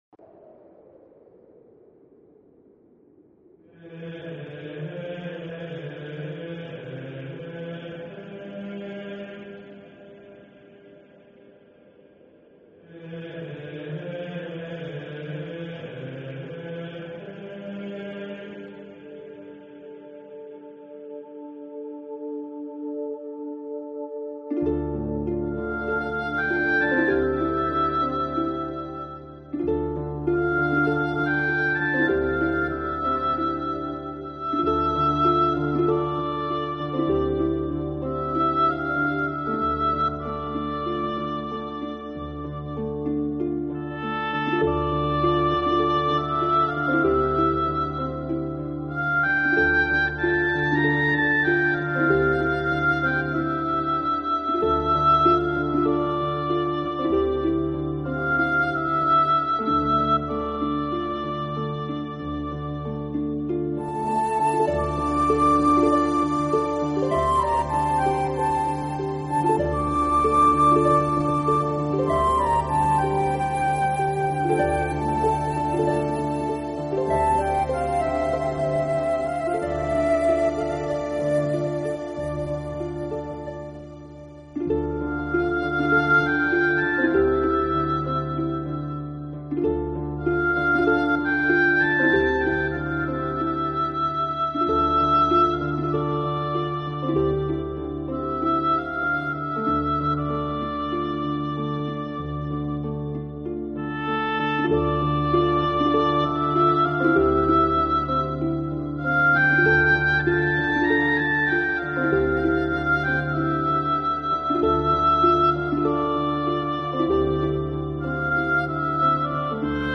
Genre..........: New Age
helps create a relaxing and inspiring atmosphere.